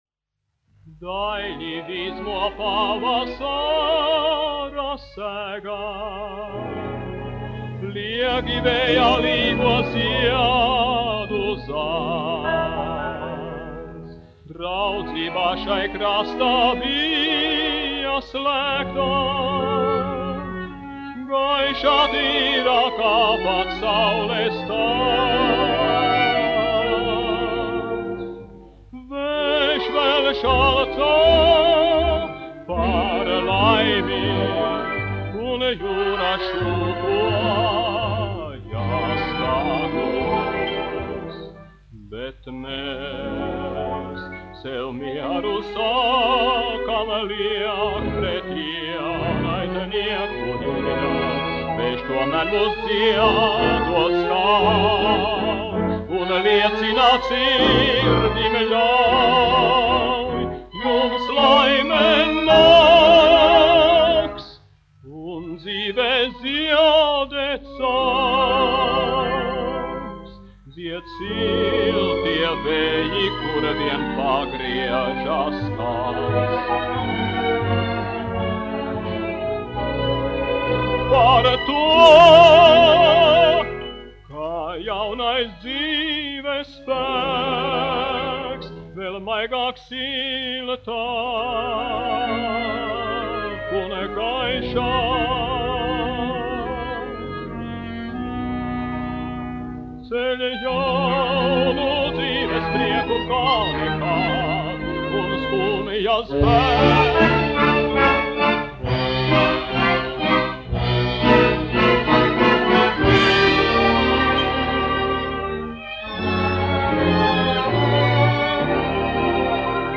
1 skpl. : analogs, 78 apgr/min, mono ; 25 cm
Dziesmas (vidēja balss) ar orķestri
Latvijas vēsturiskie šellaka skaņuplašu ieraksti (Kolekcija)